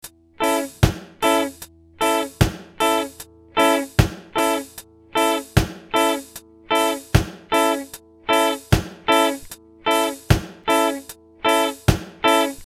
In a reggae band the rhythm guitar usually plays short staccato chords on the off beats or just on beats 2 and 4 of the bar.
Reggae A Chord | Download
Listen to the audio of the reggae skank rhythm being played on a 3 string A chord.
reggae_achord.mp3